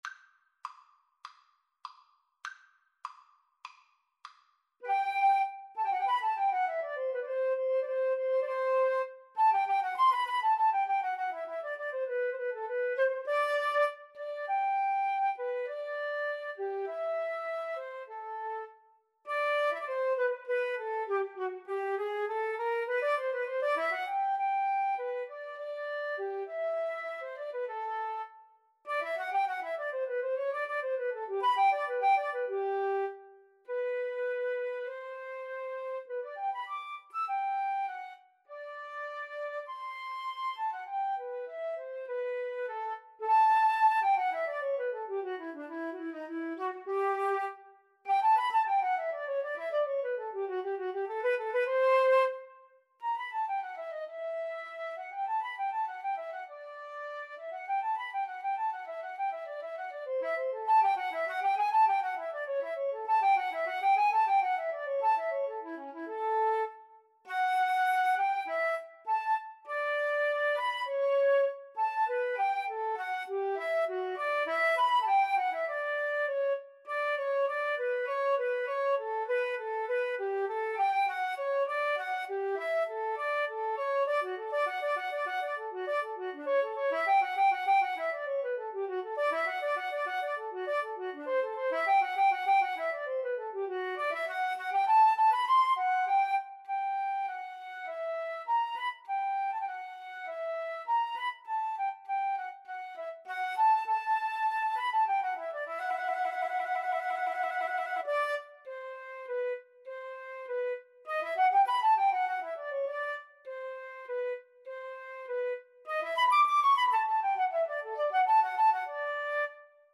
Key: G major (Sounding Pitch)
Time Signature: 4/4
Tempo Marking: ~ = 100 Allegro
Style: Classical